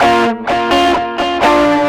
Track 10 - Guitar 04.wav